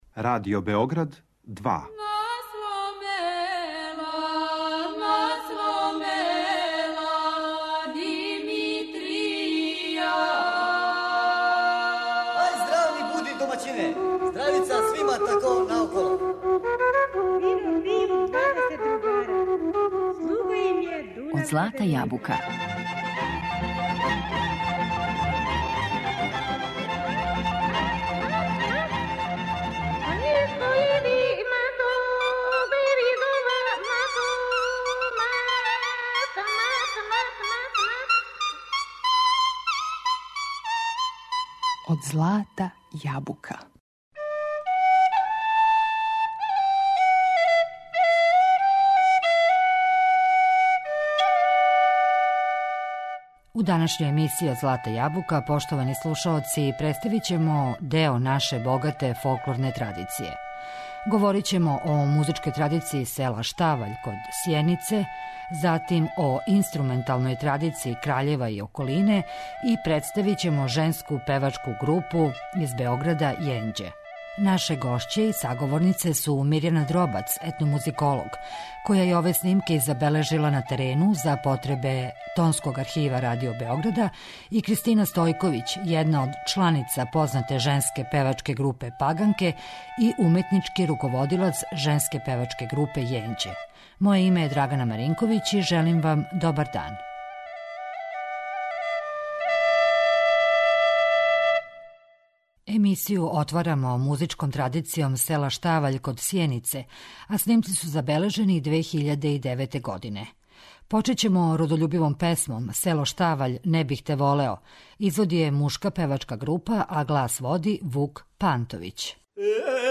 У данашњој емисији представићемо део наше богате фолклорне музичке традиције.
Говорићемо о музичкој традиције села Ставаљ, код Сјенице, инструменталној традицији Краљева и околине, и представићемо женску певачку групу "Јенђе", из Београда.